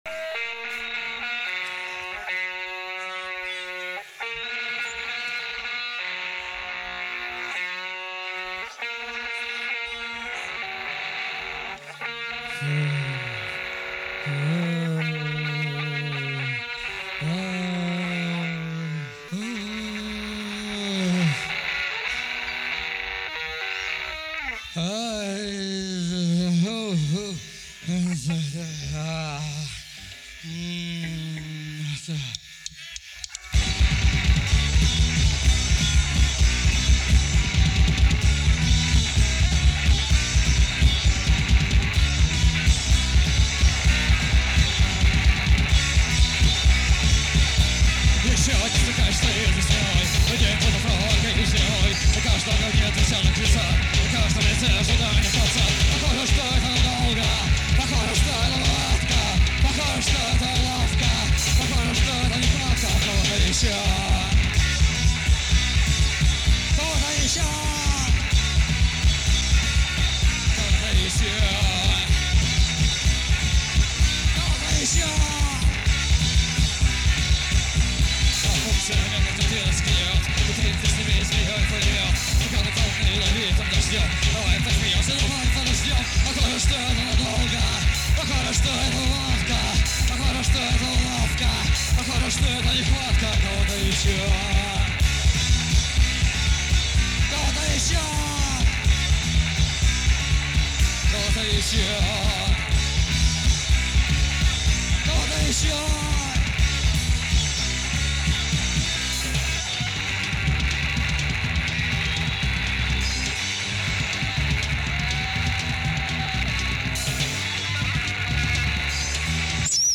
советская поэтесса, рок-певица и автор-исполнитель.
(Live)